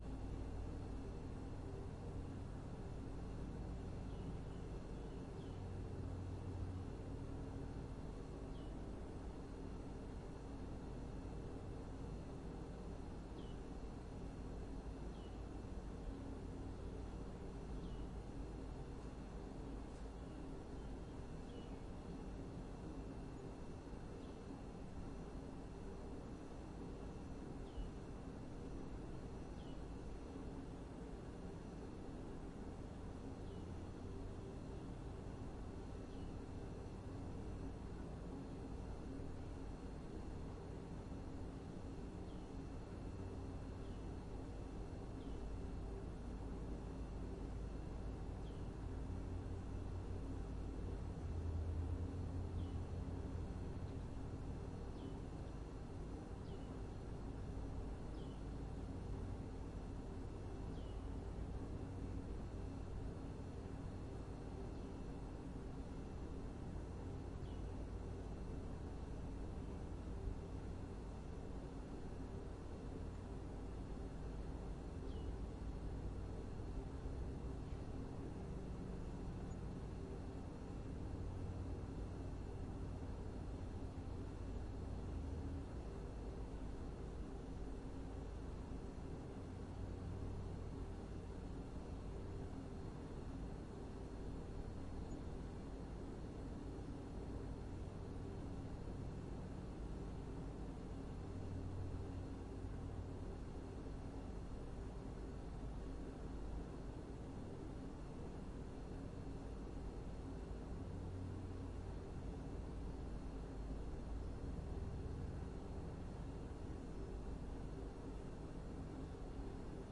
房通屋开光鸟 冰箱
描述：Roomtone，House，Open，Lite BirdsFridge。
Tag: 遥远 环境 交通 空气 安静的 背景 住宅 办公室 房间 色调 大气